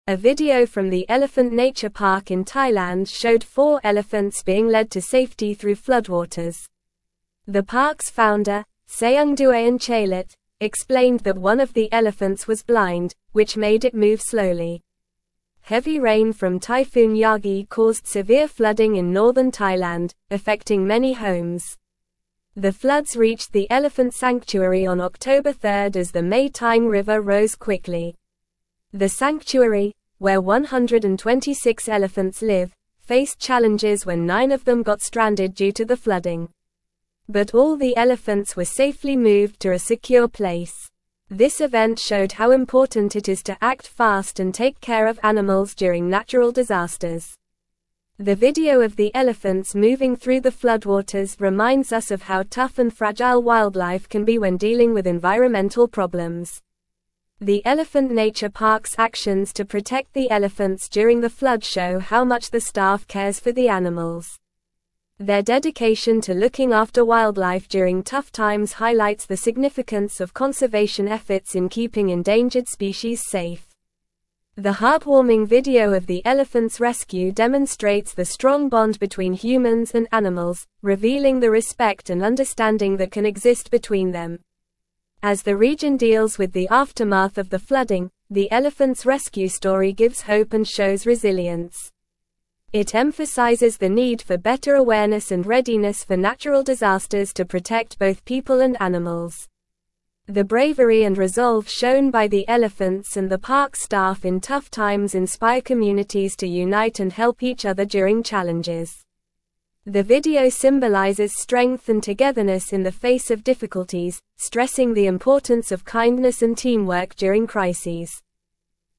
Normal
English-Newsroom-Upper-Intermediate-NORMAL-Reading-Elephants-rescued-from-floodwaters-in-Thailand-sanctuary.mp3